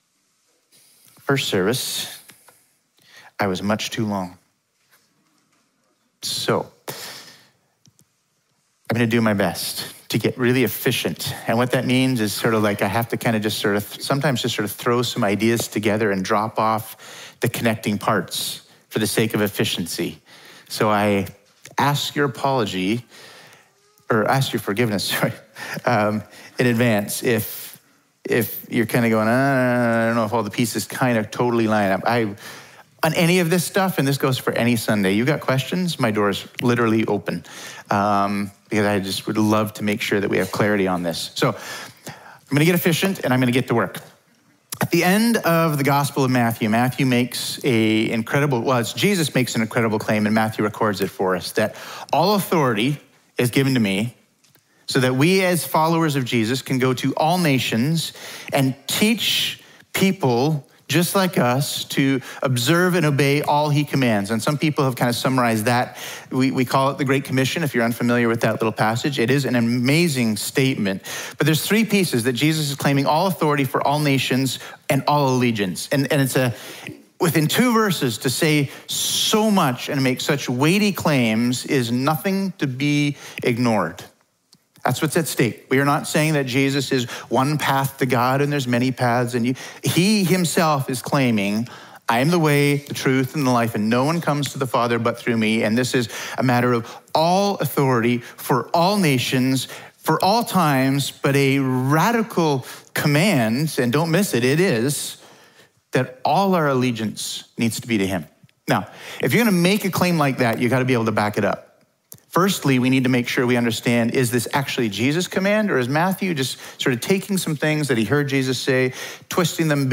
Sermons | Emmanuel Baptist Church